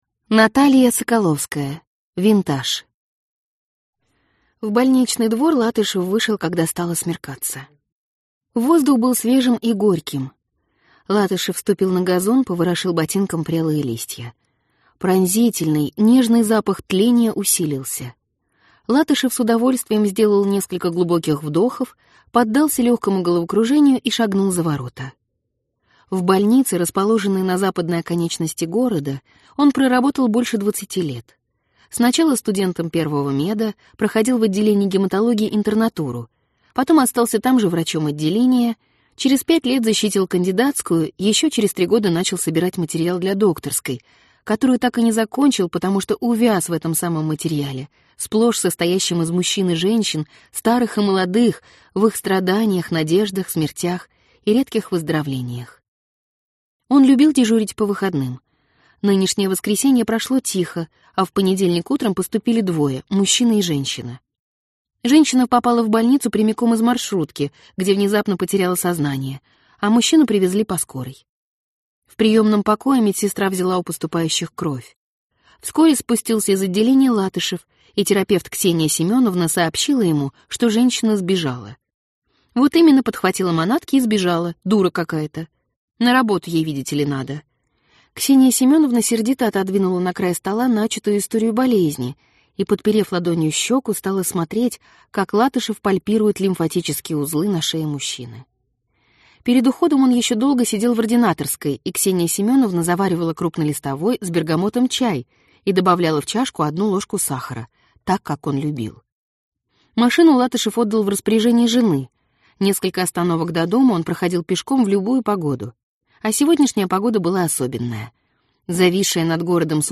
Аудиокнига Винтаж. повесть | Библиотека аудиокниг